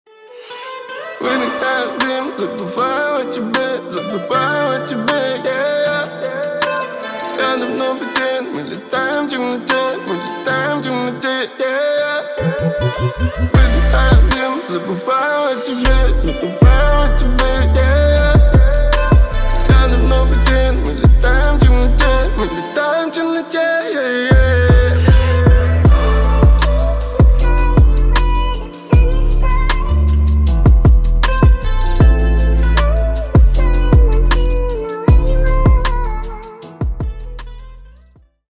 спокойные